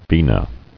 [ve·na]